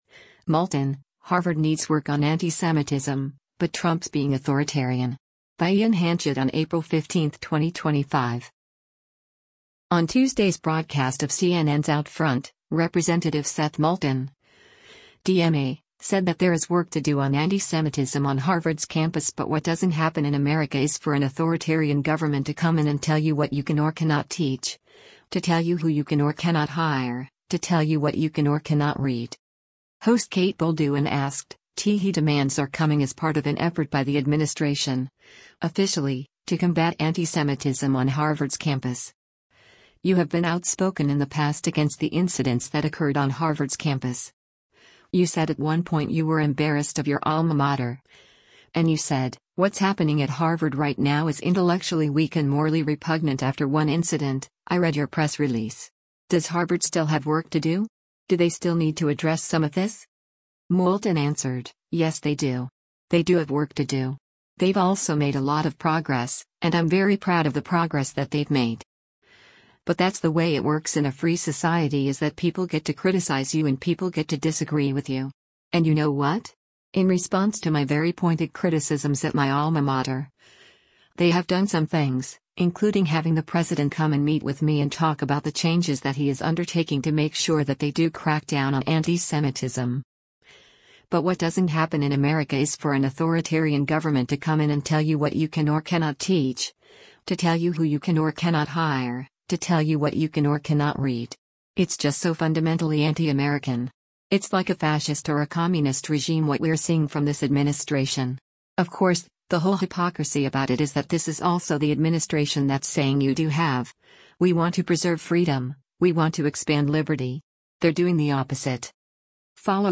On Tuesday’s broadcast of CNN’s “OutFront,” Rep. Seth Moulton (D-MA) said that there is work to do on antisemitism on Harvard’s campus but “what doesn’t happen in America is for an authoritarian government to come in and tell you what you can or cannot teach, to tell you who you can or cannot hire, to tell you what you can or cannot read.”